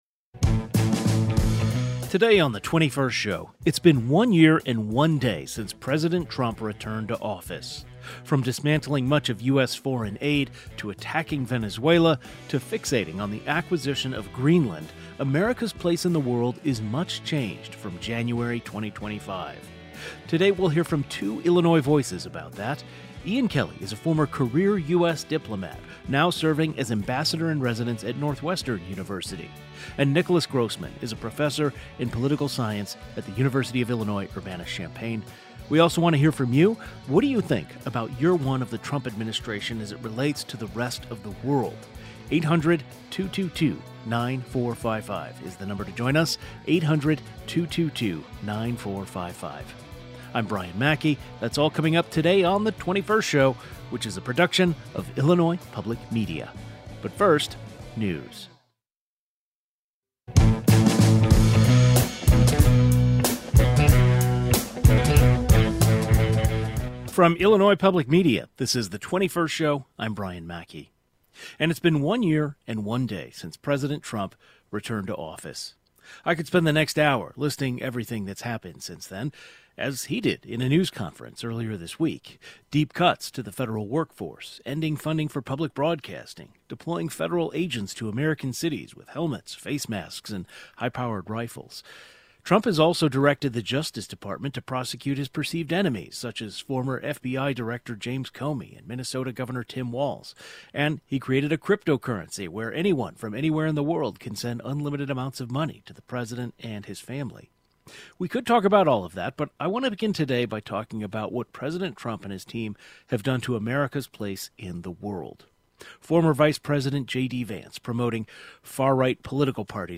A political science professor and a former U.S. ambassador weigh in on America's place in the world from recent rhetoric around Greenland to policies toward Russia and Ukraine.